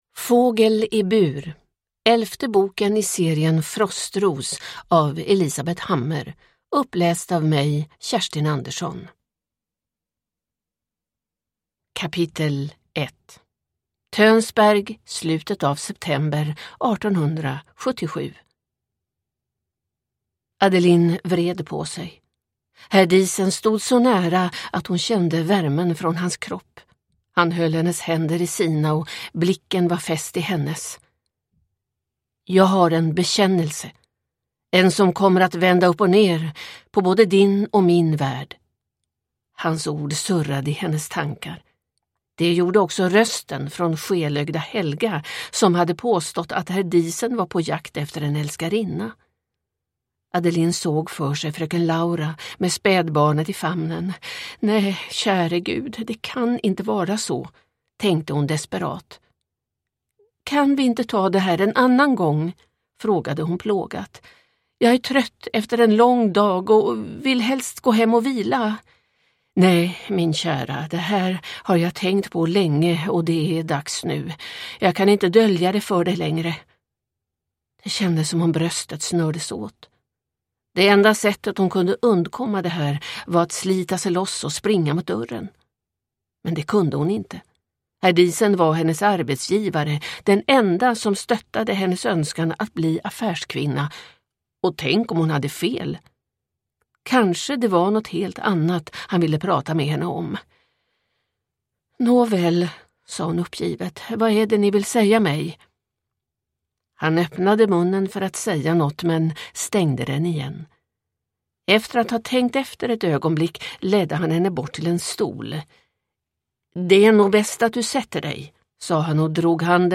Fågel i bur – Ljudbok